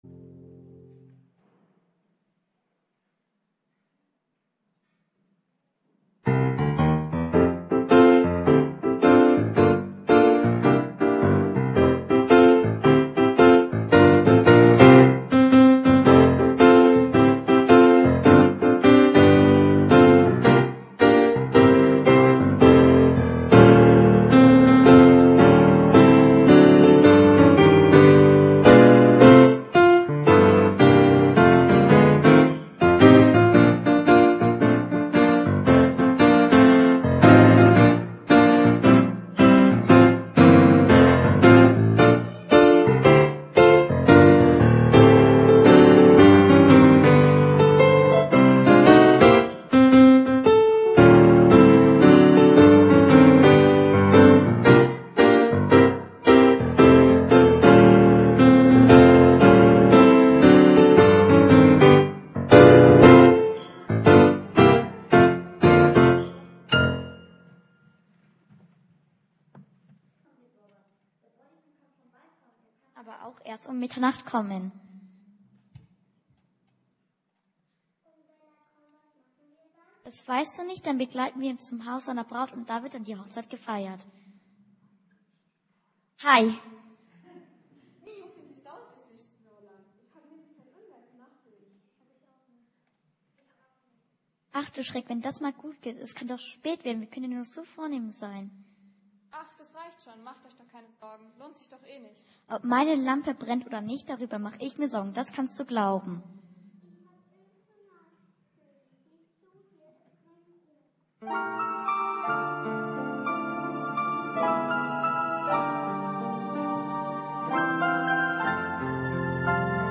Pfadfindergottesdienst
Predigten